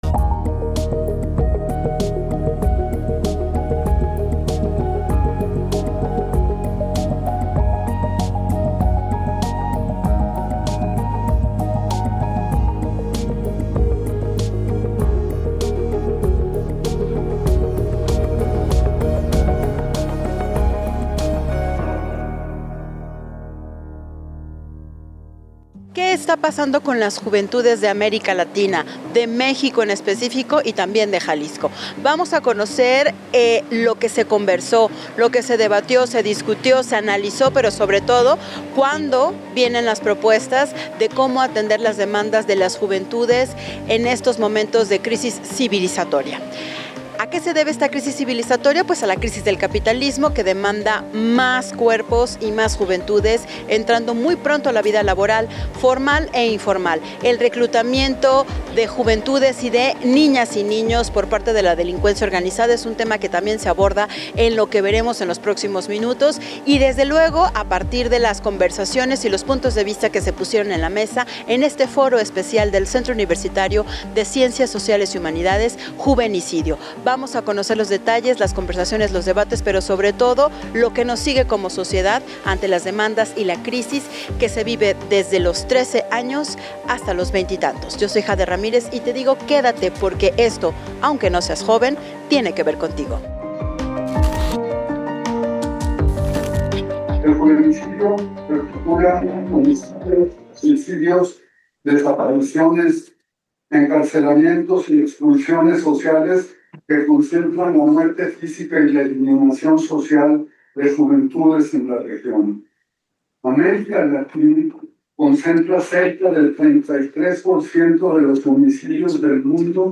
En Para Tomarlo en Cuenta, académicas y especialistas en juventudes alertan sobre los riesgos que enfrentan las nuevas generaciones ante la influencia y manipulación de la ultraderecha en espacios de gobierno y toma de decisiones.